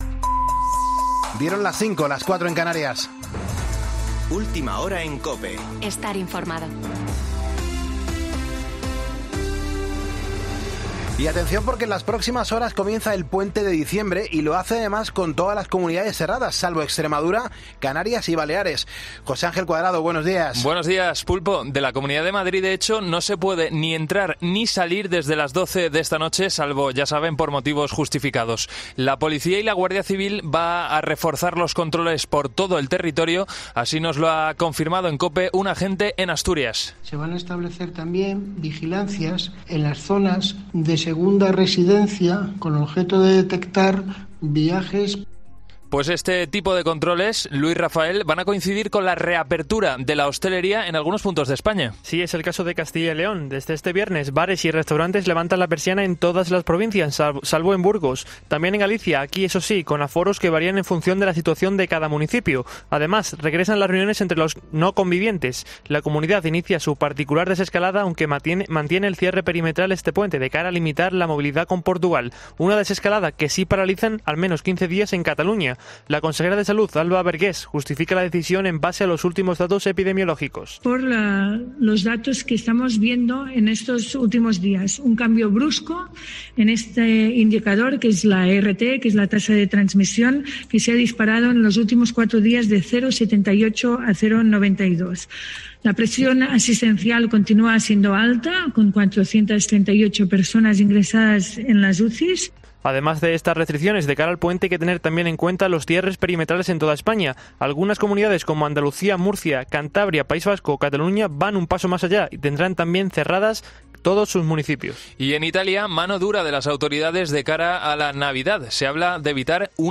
Boletín de noticias COPE del 04 de diciembre de 2020 a las 05.00 horas